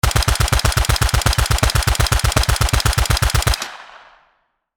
Download Free Guns Sound Effects | Gfx Sounds
M16-assault-rifle-full-auto-fire-2.mp3